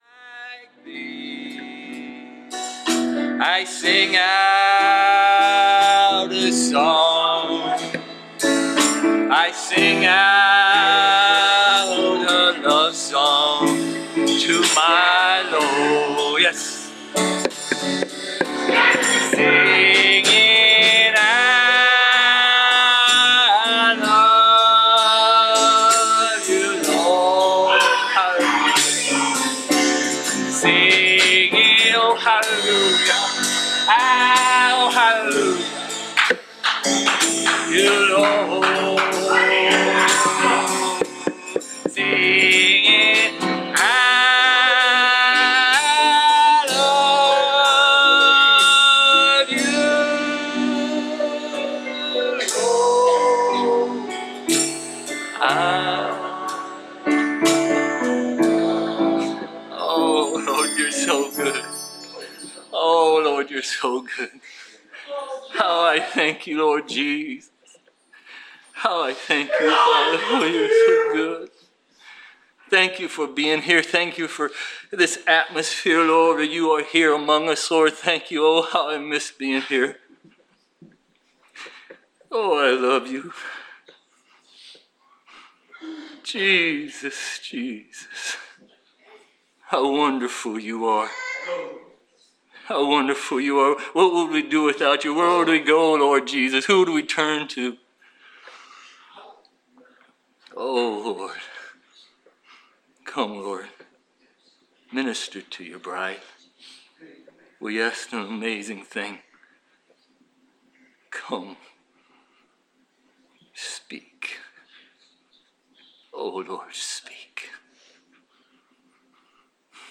Preached August 13, 2017